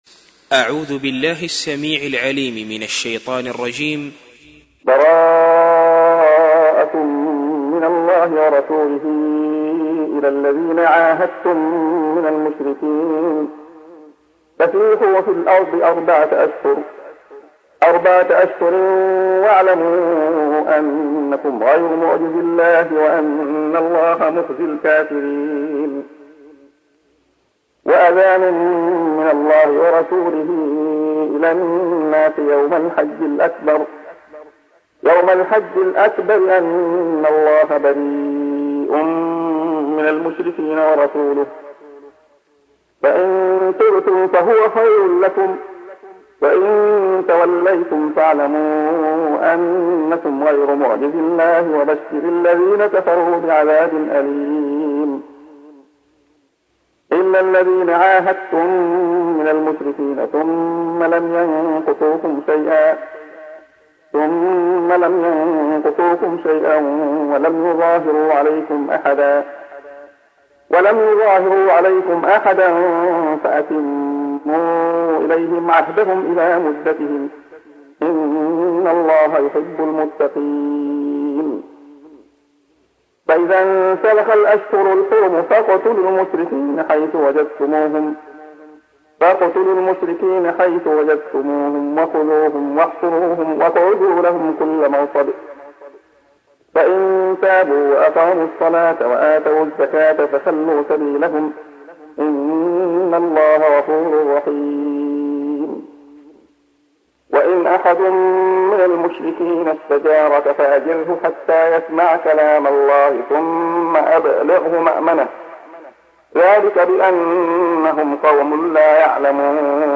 تحميل : 9. سورة التوبة / القارئ عبد الله خياط / القرآن الكريم / موقع يا حسين